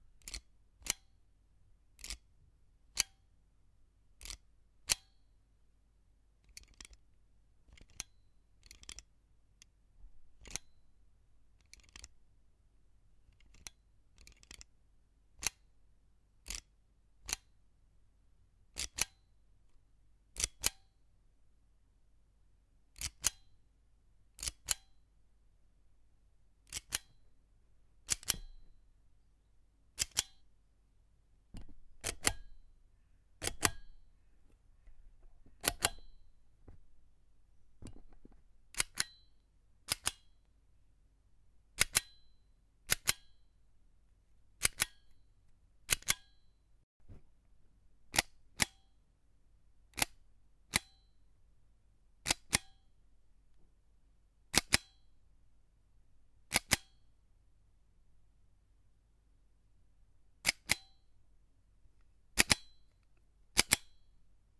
家居用品 " 金属冰淇淋勺挤压单体 192000
描述：挤压弹簧加载的金属冰淇淋勺
Tag: 挤压 装置 铰链 纹理 金属 锁存器